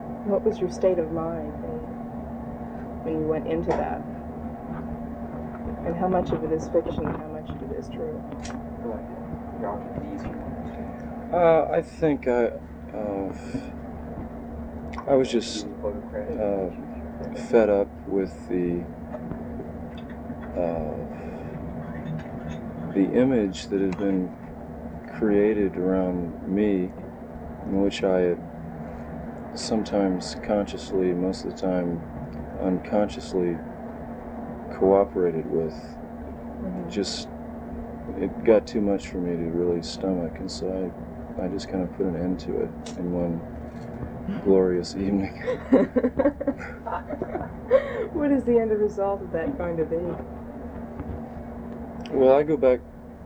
10 What Was Your State of Mind in Miami (The Lost Interview Tapes - Volume Two).flac